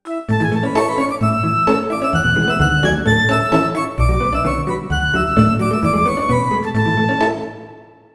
Tonalidad de La mayor. Ejemplo.
desenfadado
festivo
jovial
melodía
sintetizador